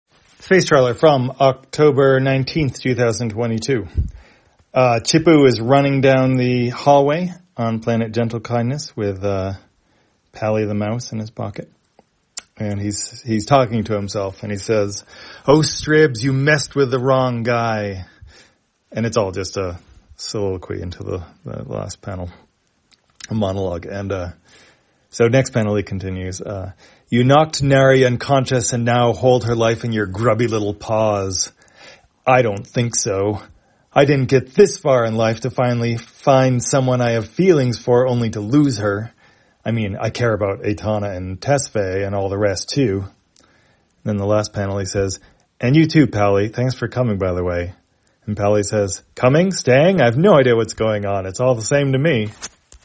Spacetrawler, audio version For the blind or visually impaired, October 19, 2022.